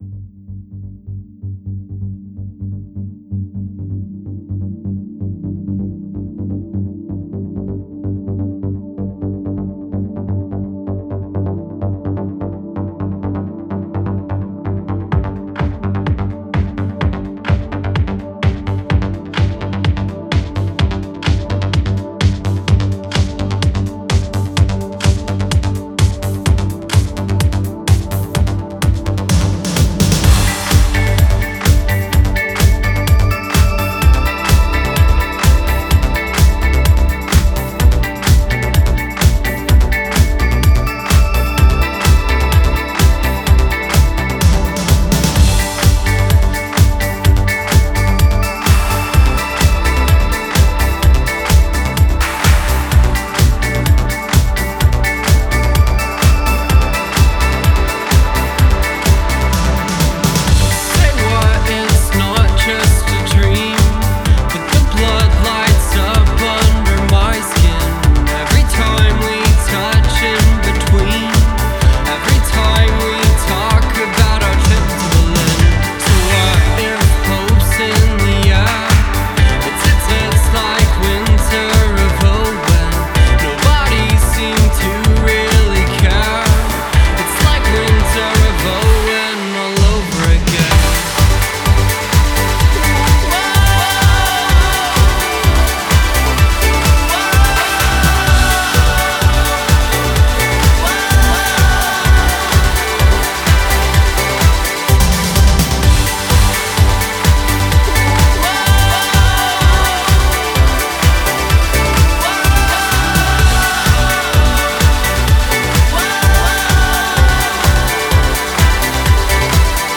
This one is a free remix of German indie group